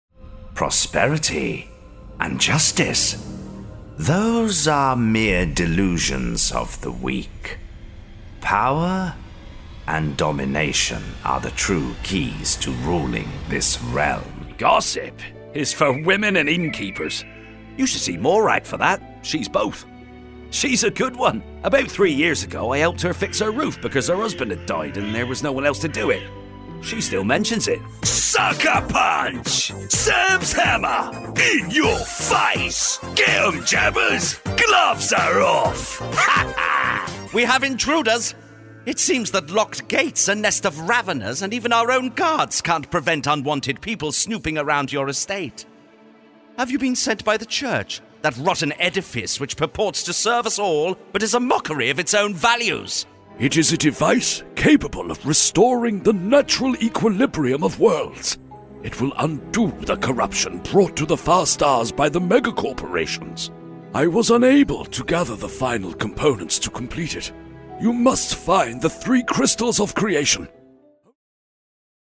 Inglés (Reino Unido)
Videojuegos
EV RE-20, interfaz UAD
BarítonoBajoProfundoBajo
CálidoConversacionalCreíbleVersátilDiversiónAmistosoCon carácter